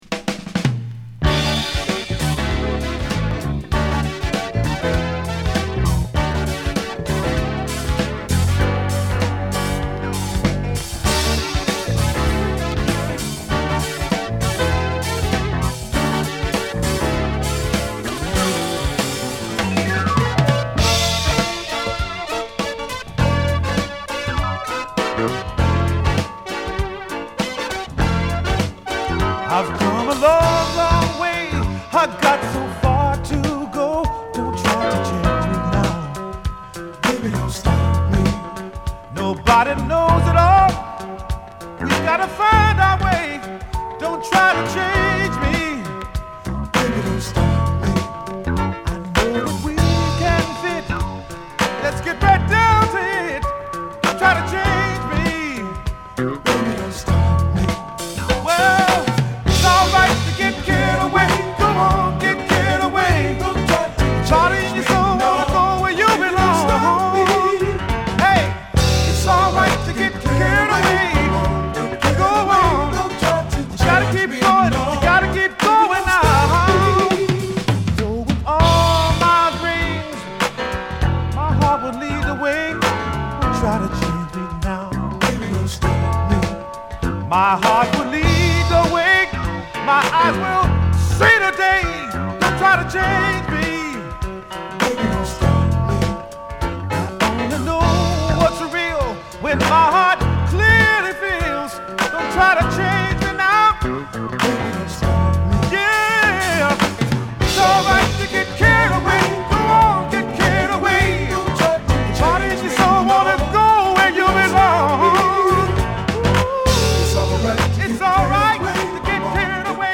シティ感あるシンセサウンドのイントロからカッコ良い、ファンクを忍ばせたアーバンソウル！
(Stereo)